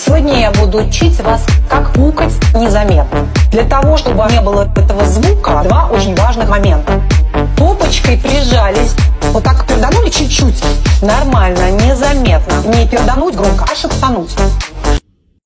веселые
mash up
смешные